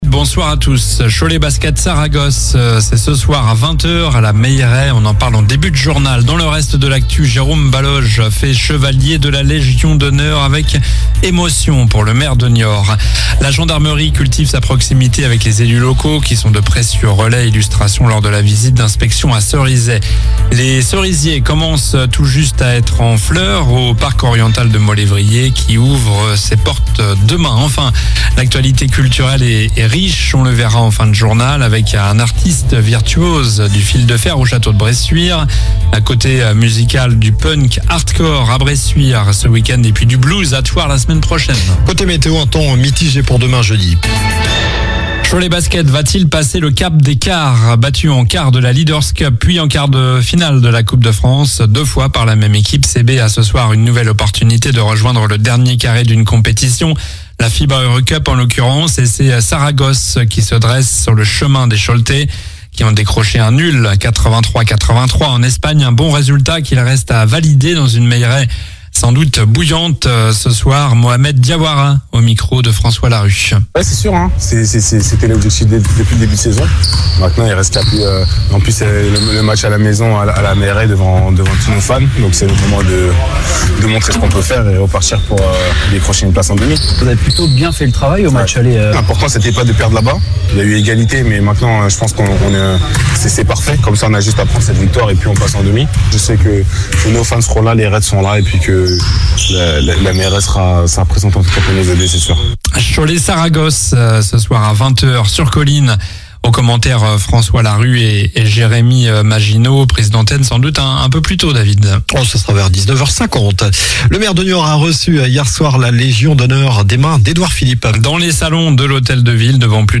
Journal du mercredi 12 mars (soir)